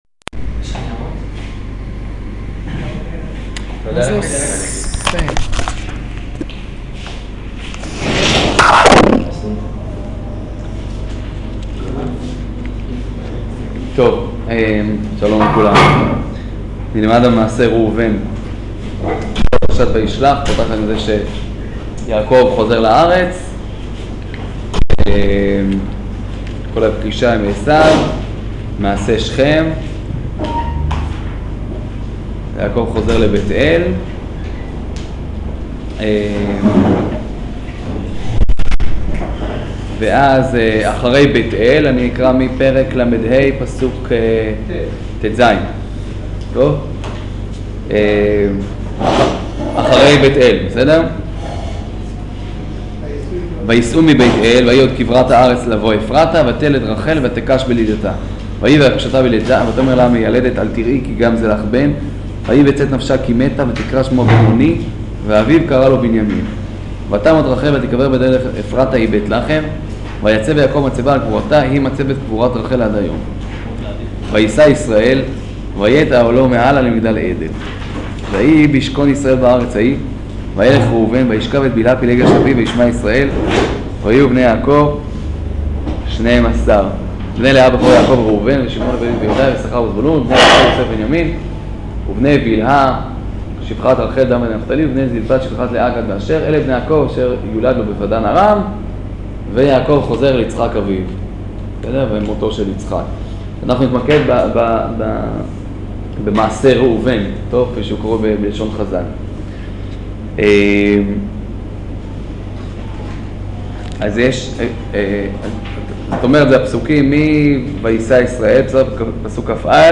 שיעור פרשת וישלח